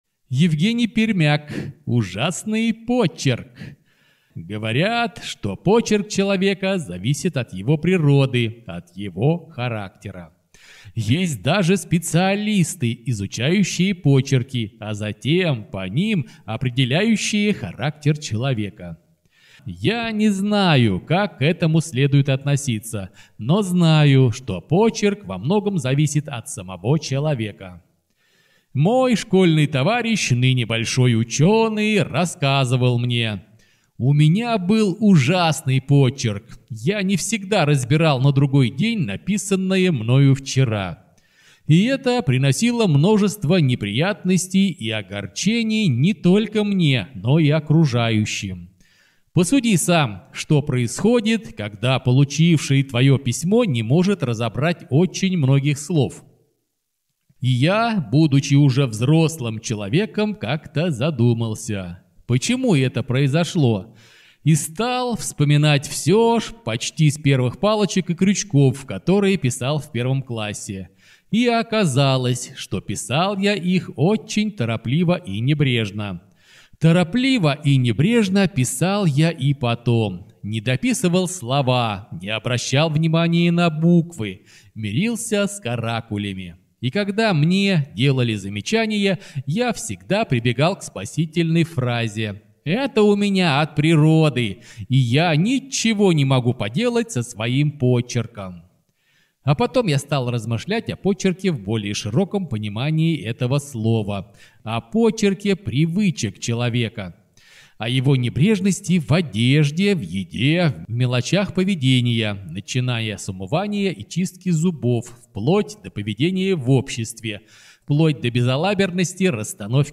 Аудиорассказ «Ужасный почерк»